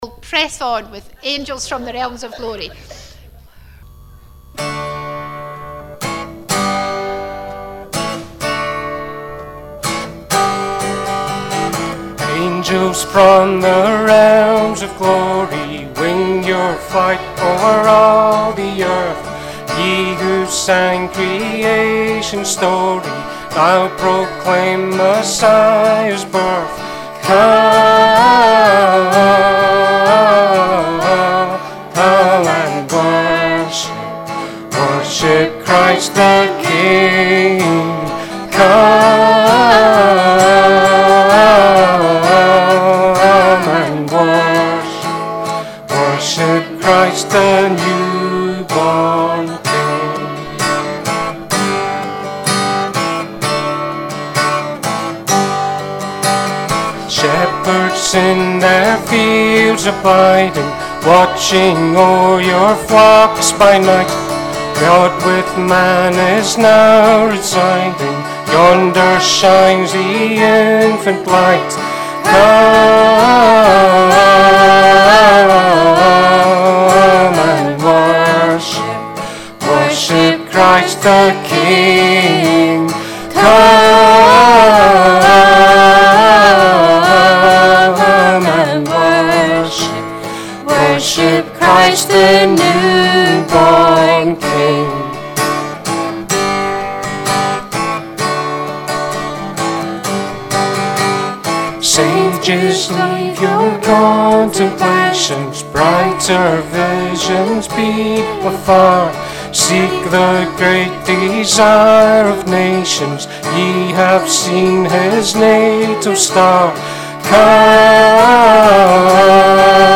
Fourth Advent Afternoon Family Service